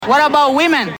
Tags: Guillermo Guillermo diaz Guillermo celebrity interview Guillermo from Kimmel Guillermo on Jimmy Kimmel show